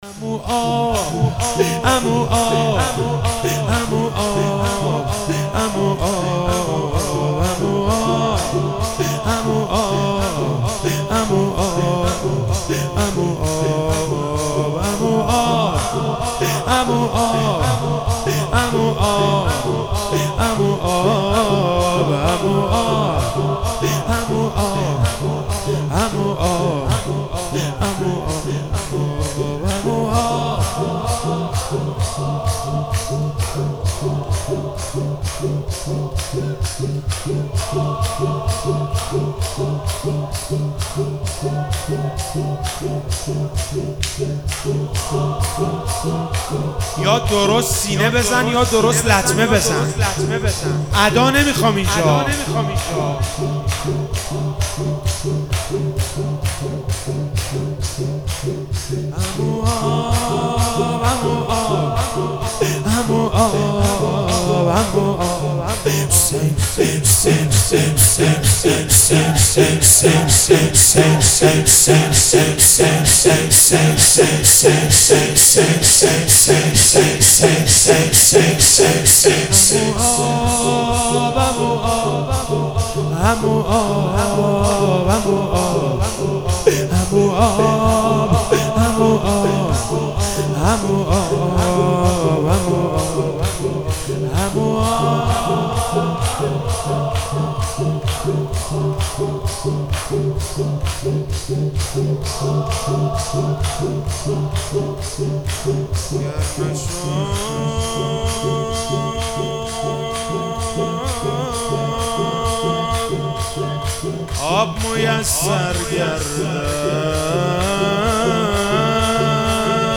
محرم 98 شب هفتم - شور - عمو آب